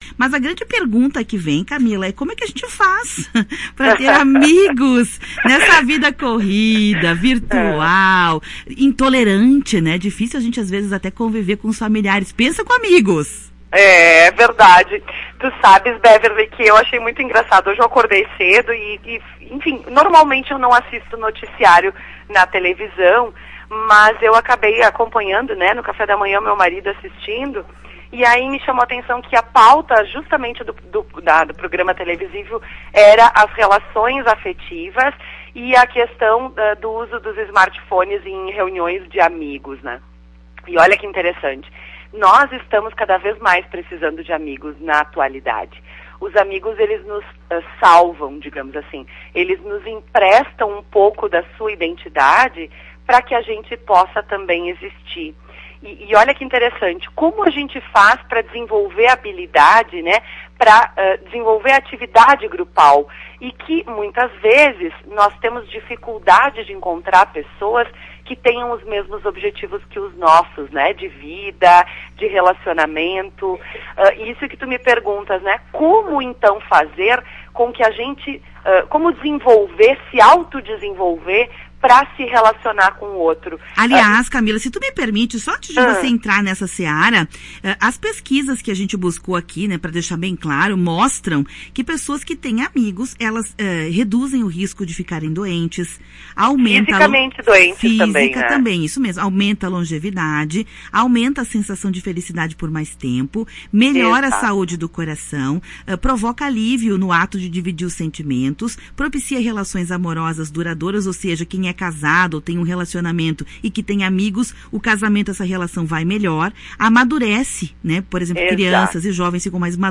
Psicóloga explica os benefícios da amizade para a saúde